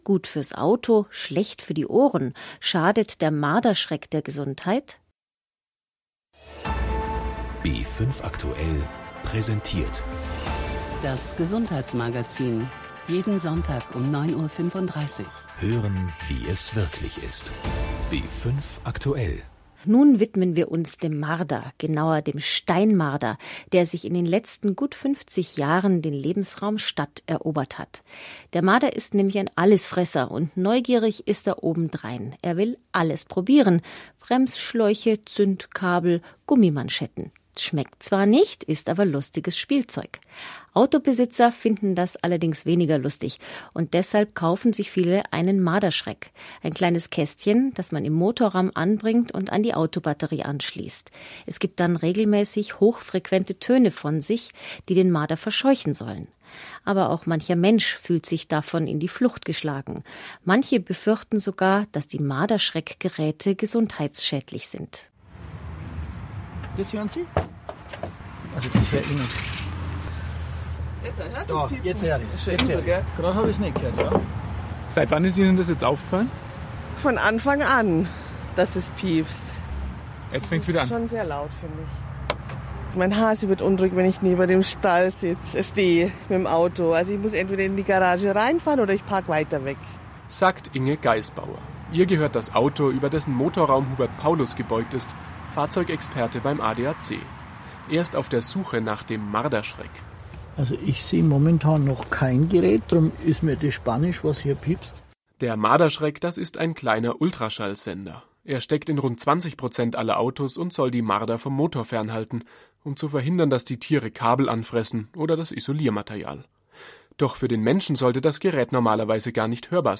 (* Vorsicht mit der Lautstärke. Der gut hörbare Pieps Ton könnte in Kinderohren schmerzen!)
- Eine Frau hört den in ihrem Auto eingebauten Marderschreck über einen nicht genau definierten Zeitraum (... von Anfang an).